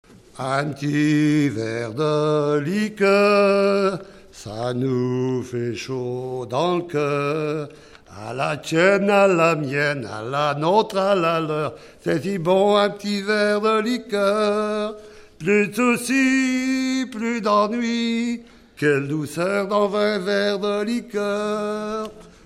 circonstance : bachique
Genre brève
Enregistrement de chansons
Pièce musicale inédite